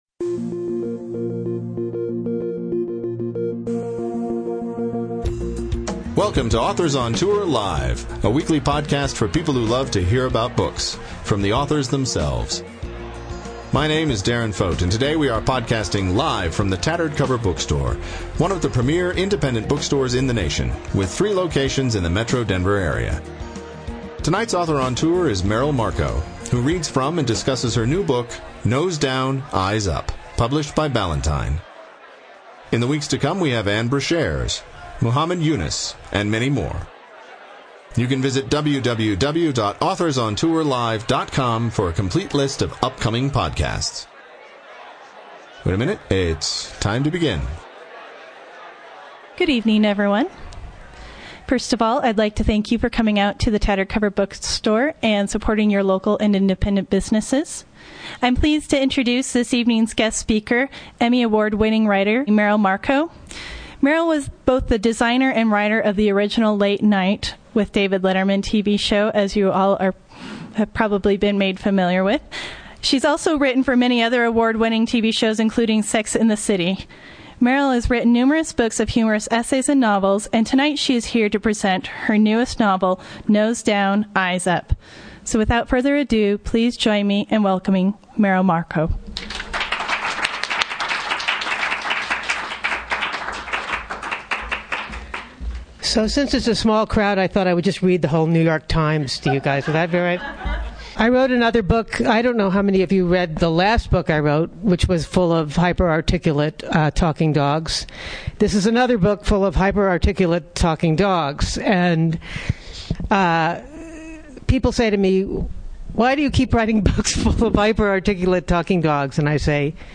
Merrill Markoe, the Emmy Award-winning writer and creator of Stupid Pet Tricks, reads from and discusses Nose Down, Eyes Up, her second fun-loving doggie novel, following Walking in Circles Befor
Browse > Home / Book Store Events / Merrill Markoe Podcasts Nose Down, Eyes Up | Authors On Tour - Live!